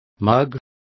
Also find out how tazon is pronounced correctly.